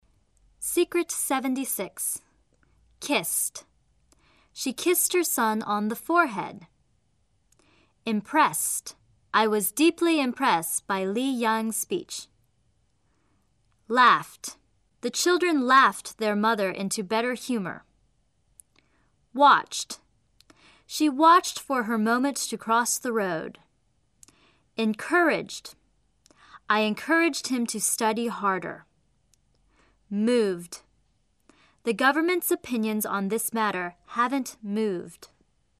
英语规则动词过去时的形式是在原形动词后面加-ed，如like[laIk]的过去时是liked[laIkt]，liked中的-d因为受原形动词词末清辅音[k]的影响读成清辅音[t]；而动词fill[fIl]的过去时filled[fIld]中的-d因为受原形动词词末浊辅音[l]的影响而读成浊辅音[d]。